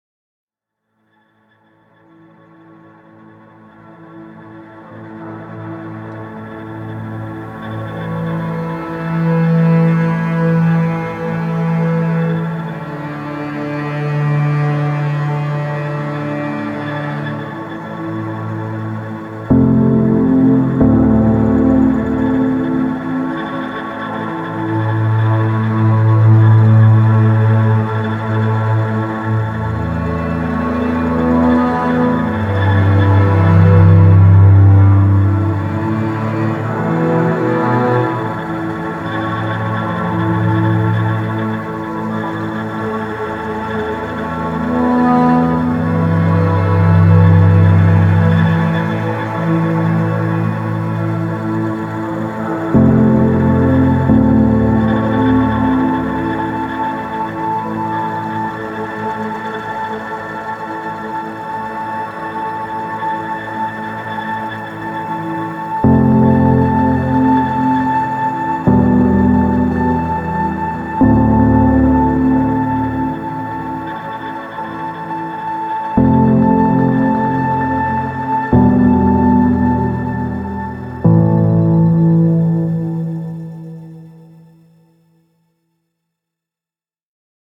Mysterious strings building a tense anticipating atmosphere.